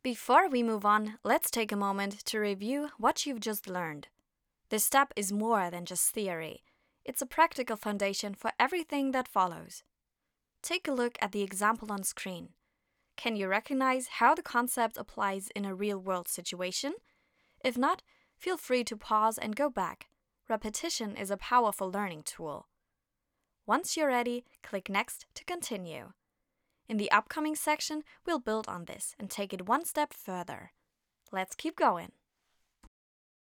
Emotionale Stimme auf Deutsch & Englisch.
• weiblich
• emotional | dynamisch | sanft |
• Junge, frische Klangfarbe, die natürlich und sympathisch klingt
E-LEARNING (ENGLISCH)